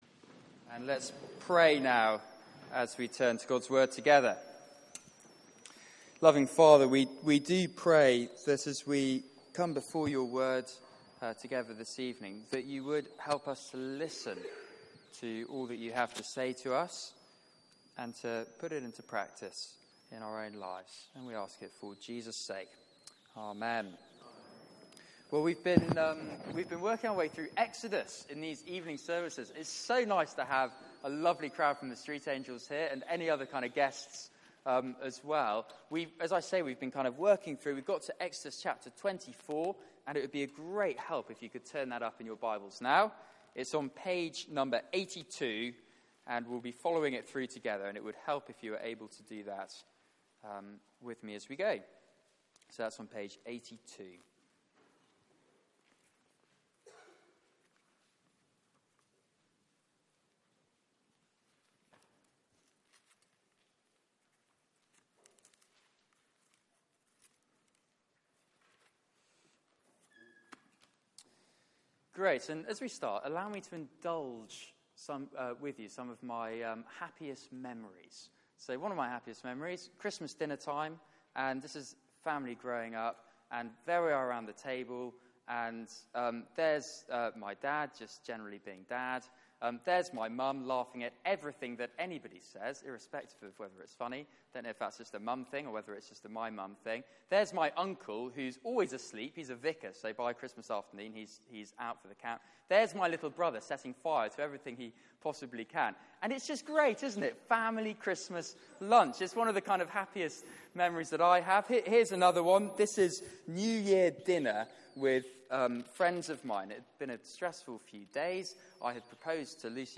Service Type: Weekly Service at 4pm